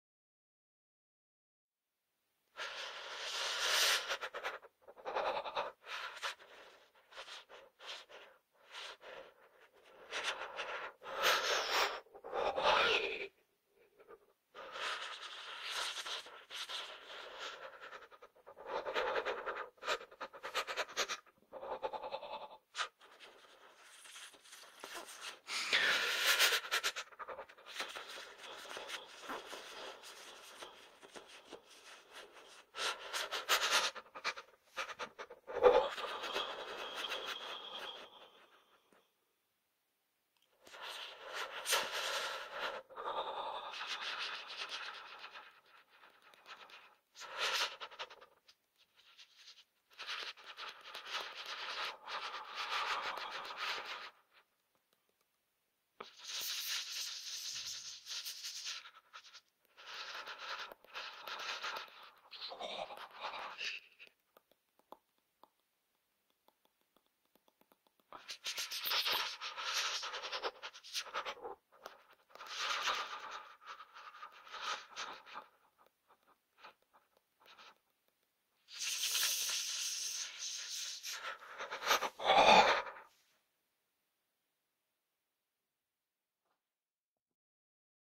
دانلود صدای یخ زدن در سرما از ساعد نیوز با لینک مستقیم و کیفیت بالا
جلوه های صوتی
برچسب: دانلود آهنگ های افکت صوتی انسان و موجودات زنده